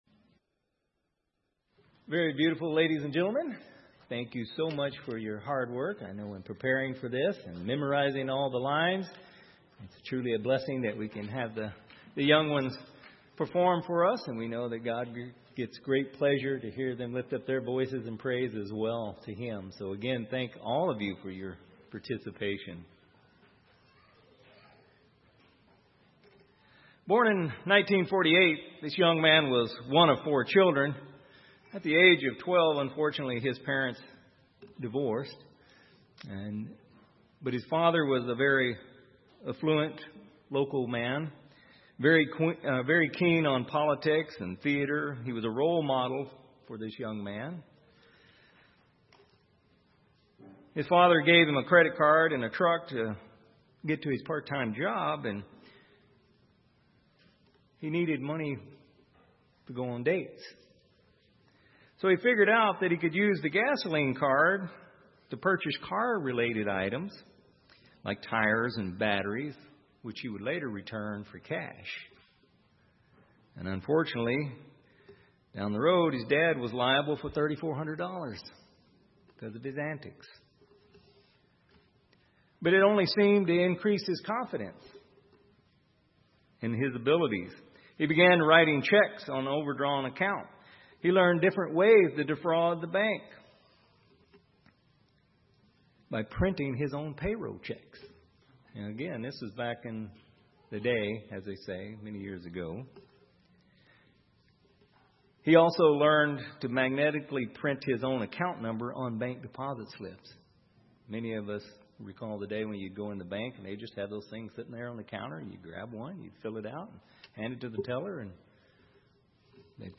Sermons
Given in Salem, OR